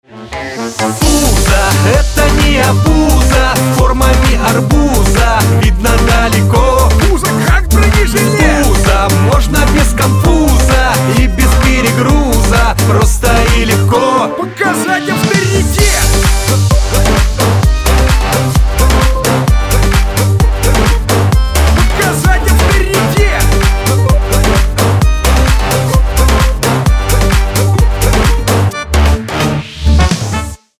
• Качество: 320, Stereo
позитивные
мужской голос
веселые